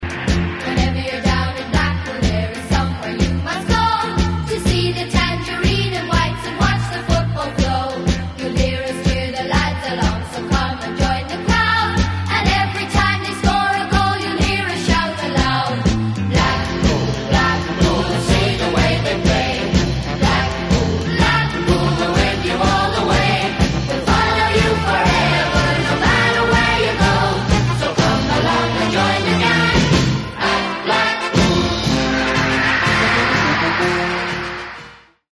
an uplifting anthem
digitally remastered